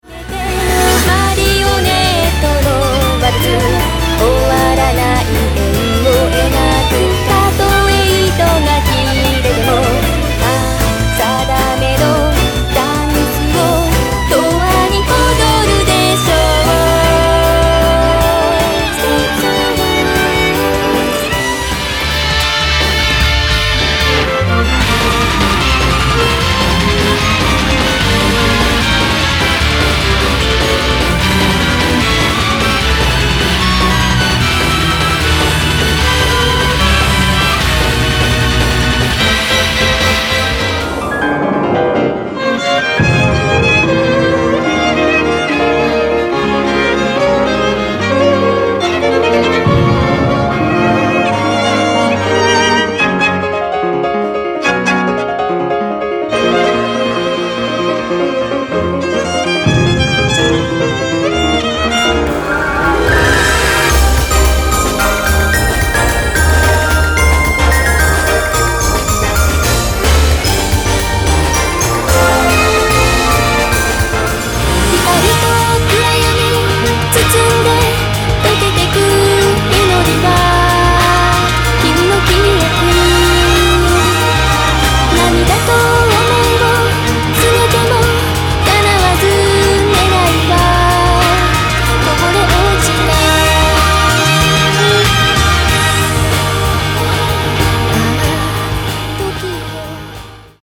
ノリの良さと生バイオリンの壮大な感動は